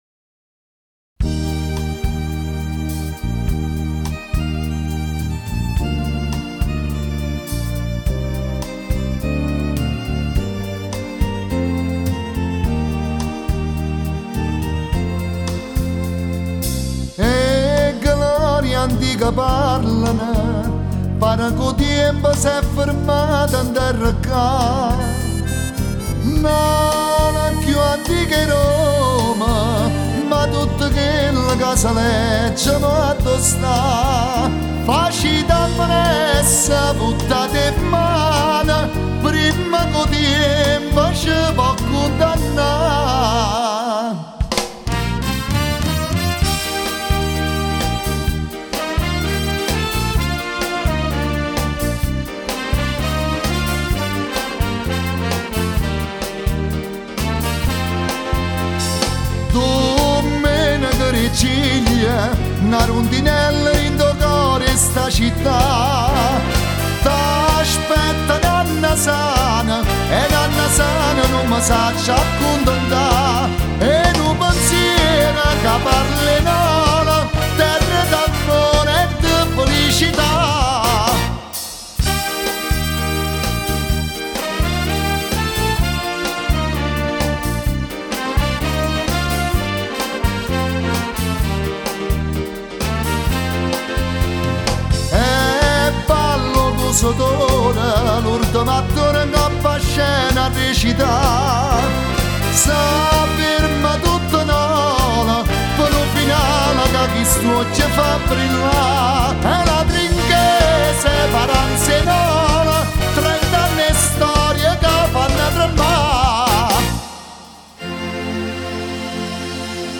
Cantanti